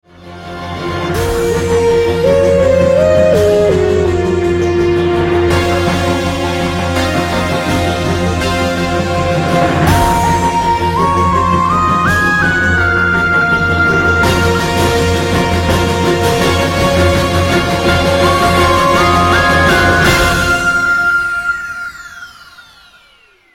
TV Theme